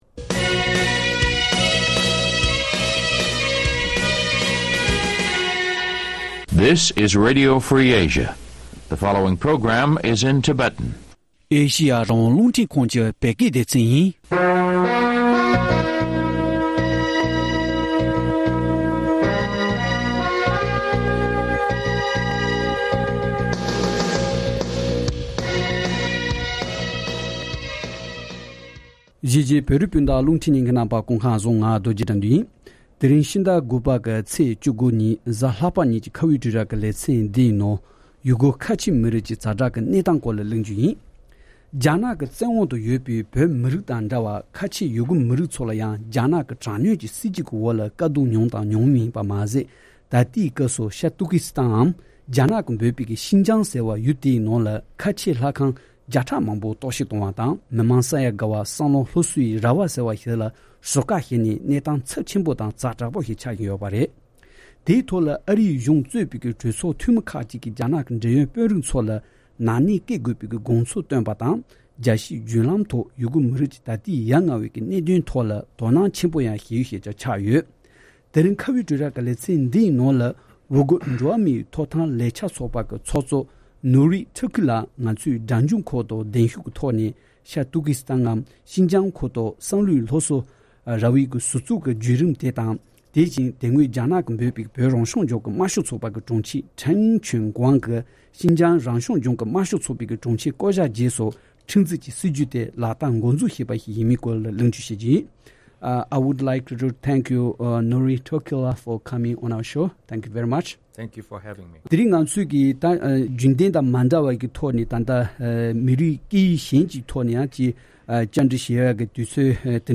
ཨ་རིའི་རྒྱལ་ས་ཝ་ཤིན་ཀྲོན་ཏུ་ཡུར་གུ་འགྲོ་བ་མིའི་ཐོབ་ཐང་ལས་འཆར་ཚོགས་པའི་ཚོགས་གཙོ་དང་ཁྲིམས་གཙོད་པ་NuriTurkelལ་ང་ཚོའི་་སྒྲ་འཇུག་ཁང་དུ་གདན་ཞུ་ཐོག་ན